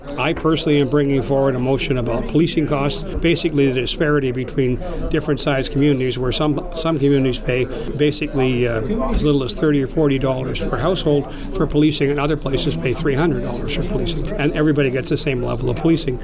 Courtenay Mayor Larry Jangula says he wants to address the disparity between different-sized communities when it comes to policing costs. He notes that some areas pay as little as $30 per household for policing while other communities can pay up to $300 per household.